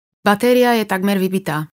Hovorí po slovensky
Vysávač hovorí v slovenskom jazyku.